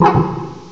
cry_not_herdier.aif